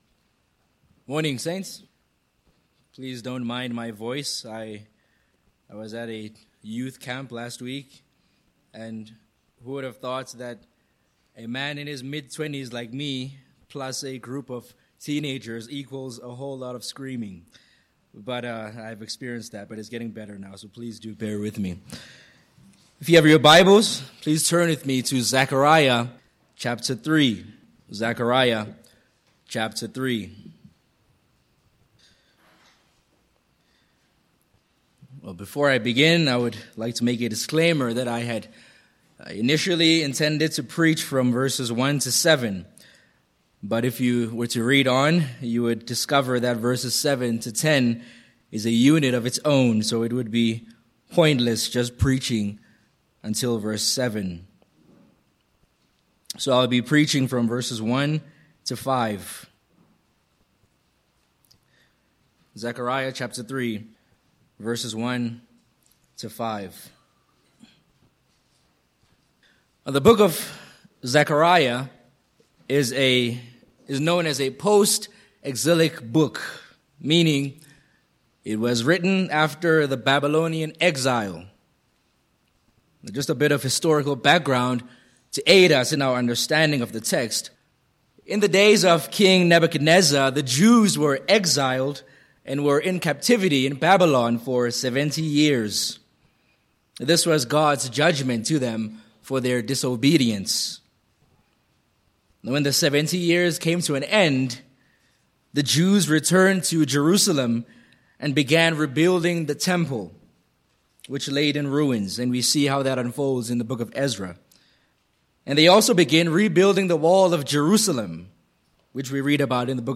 Sermon Points: 1. The Accusation of Satan v1 2. The LORD’s Response/Rebuke v2 3. Holy Vessels for Honourable Use v3-5